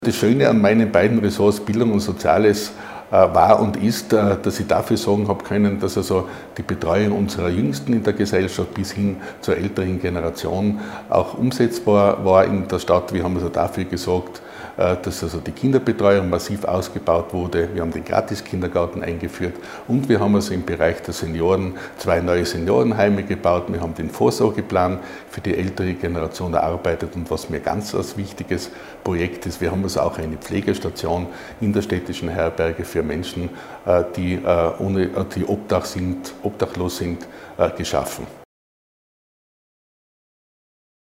OT von Stadtrat Ernst Pechlaner